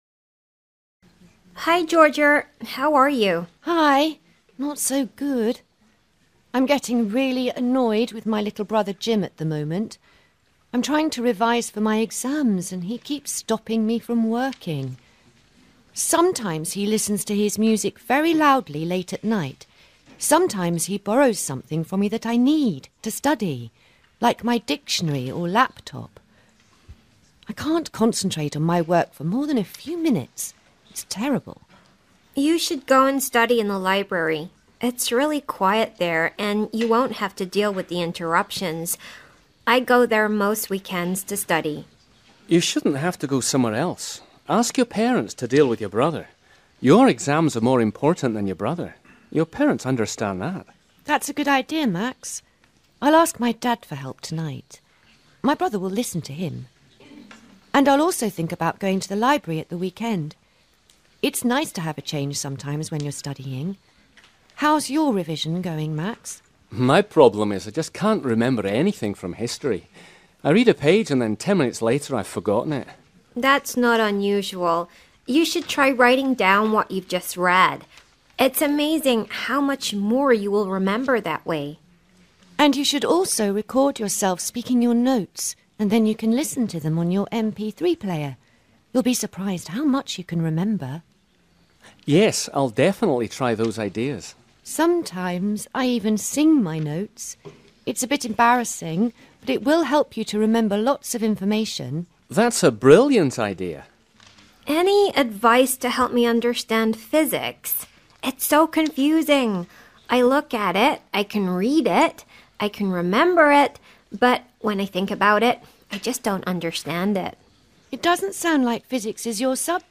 A   Listen to three friends talking about studying.